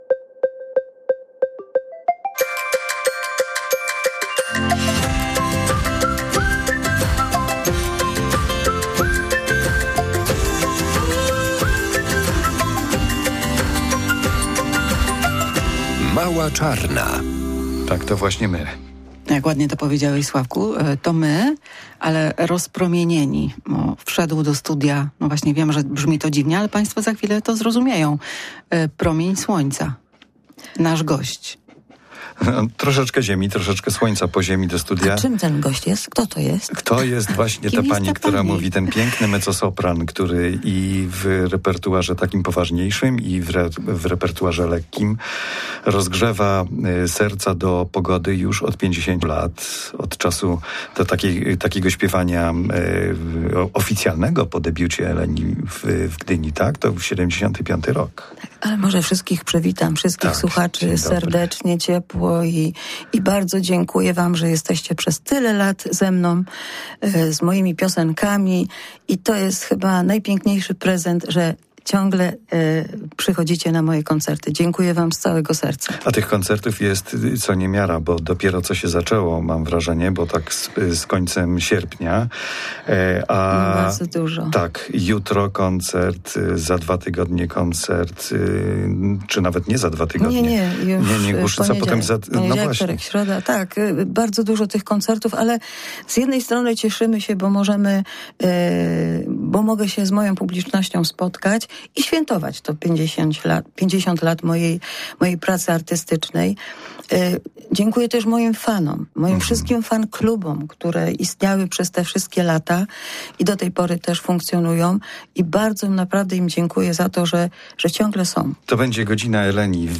Eleni świętuje 50-lecie swojej pracy artystycznej. W czwartek była gościem przedpołudniowego programu Radia Poznań "Mała czarna".
ot88e5uidlw19ee_eleni_rozmowa.mp3